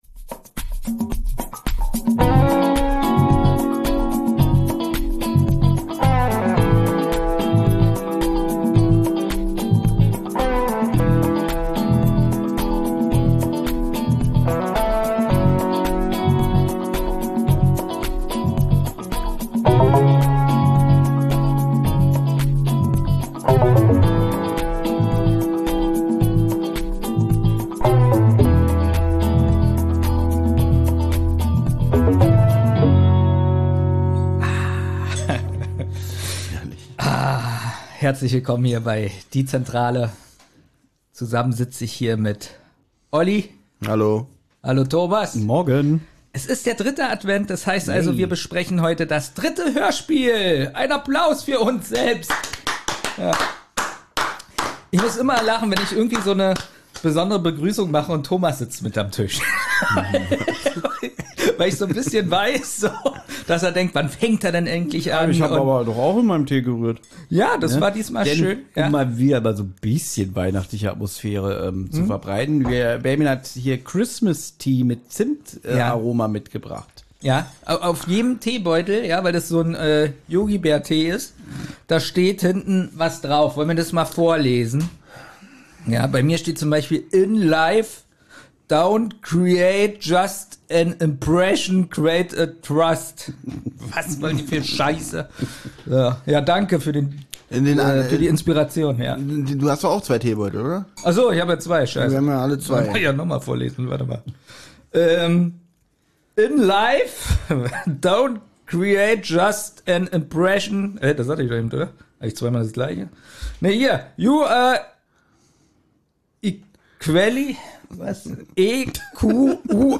Eure drei Lieblingspodcaster besprechen heute wieder ein besonderes Hörspiel für euch.